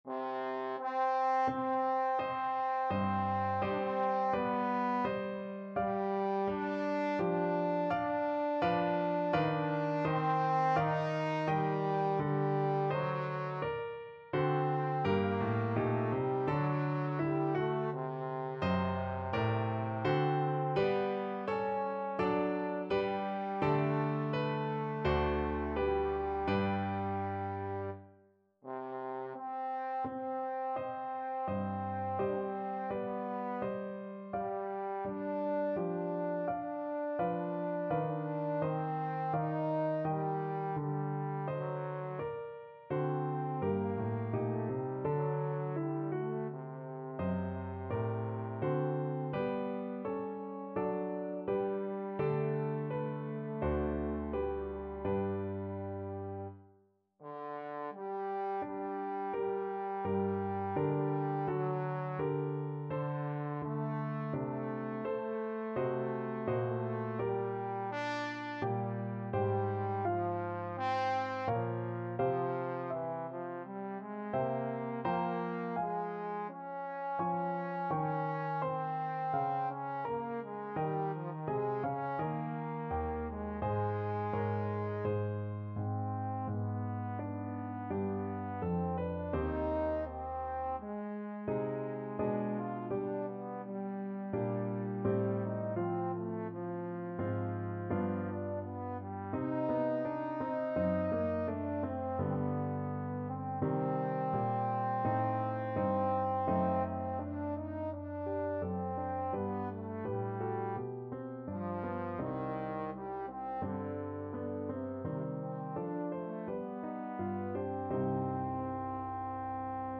Trombone
4/4 (View more 4/4 Music)
C4-F5
C minor (Sounding Pitch) (View more C minor Music for Trombone )
Largo =42
Classical (View more Classical Trombone Music)